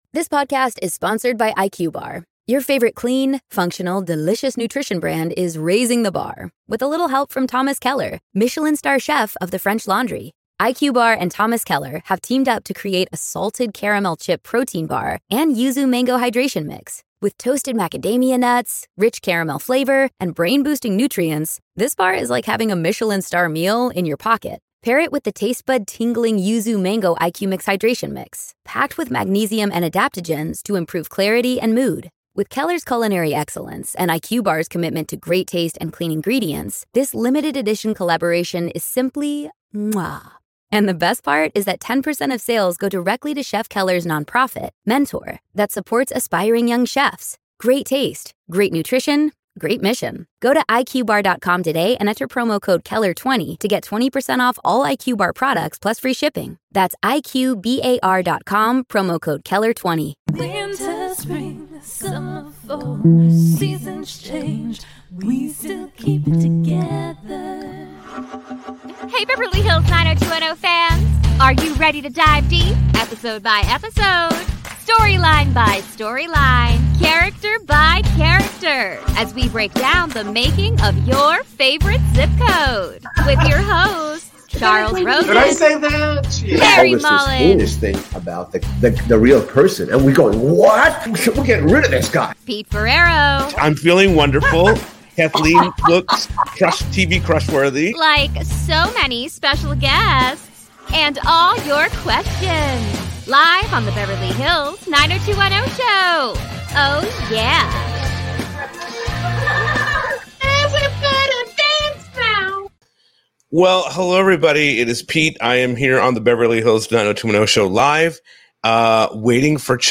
a previous interview